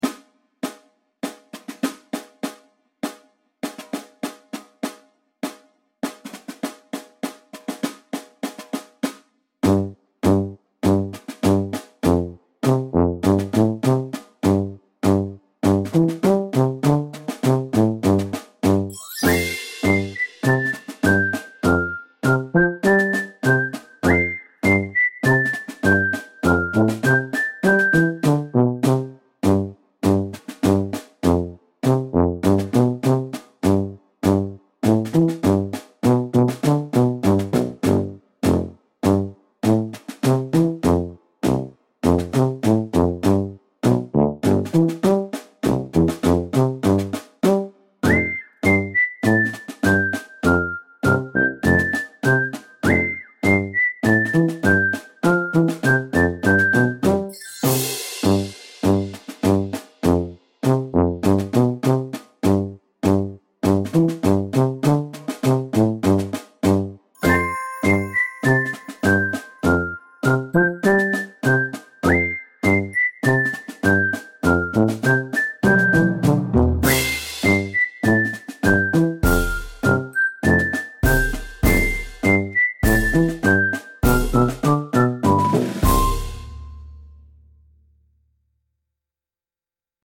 silly marching band music for kids with tuba, snare drum and penny whistle